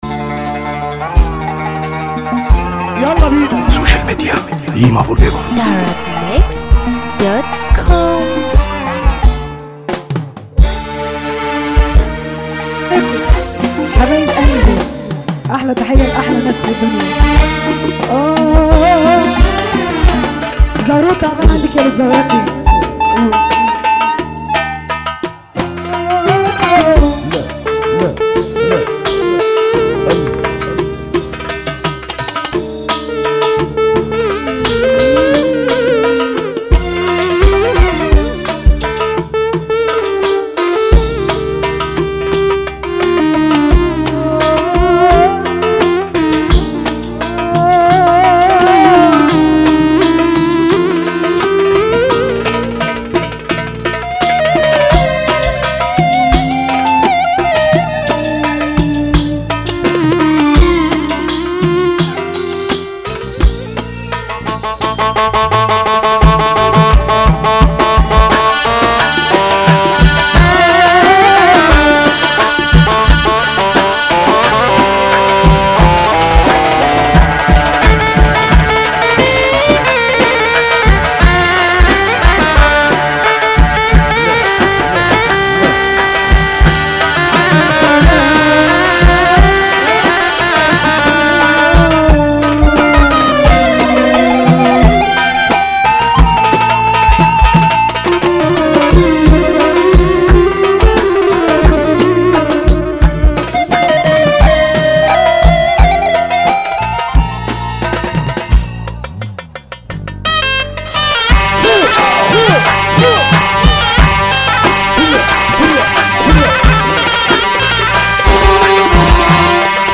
باقوى احساس